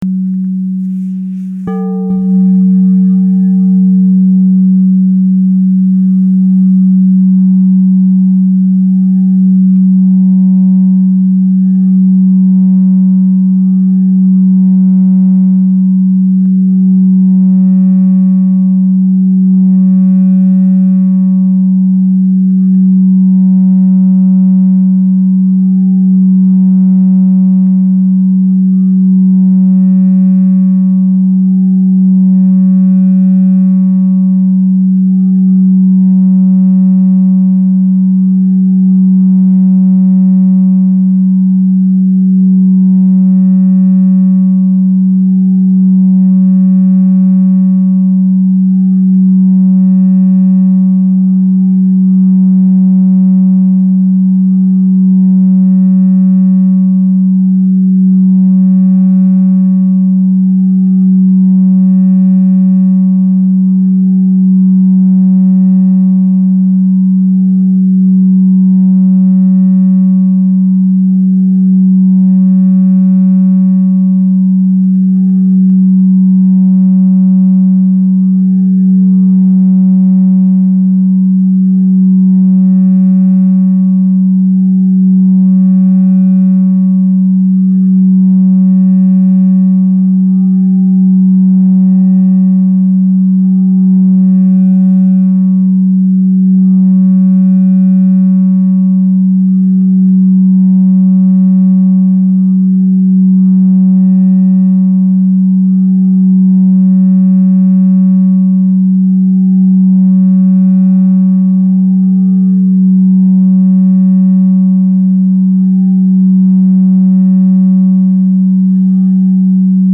■クリスタルボウル（BGMなし）
crystalbowl.mp3